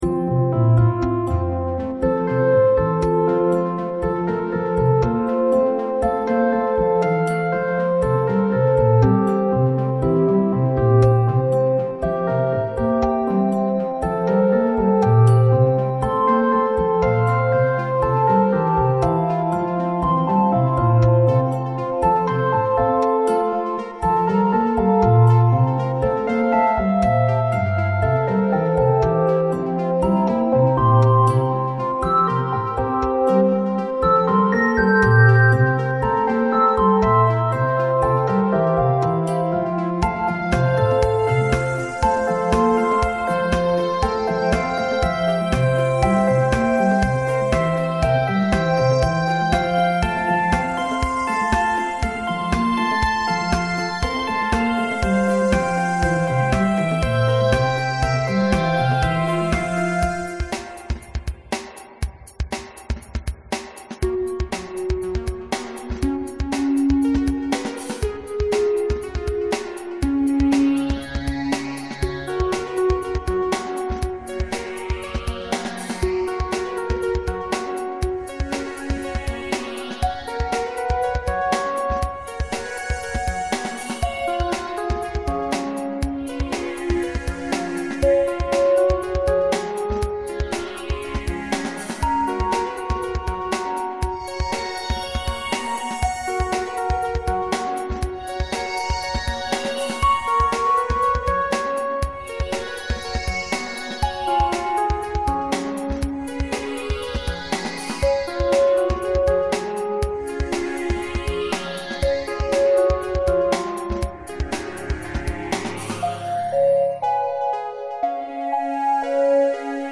After another ten bars there is a very abrupt  change.